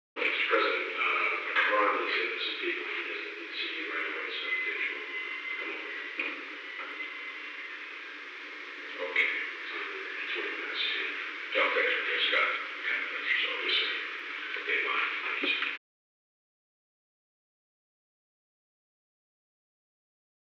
Secret White House Tapes
Conversation No. 922-4
Location: Oval Office